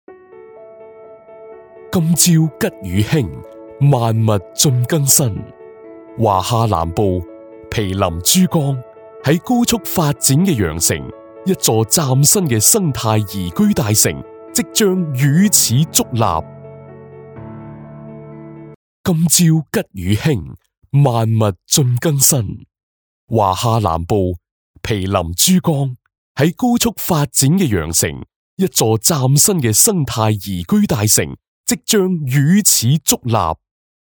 • 3广粤男声2-1
清朗悦耳-宣传片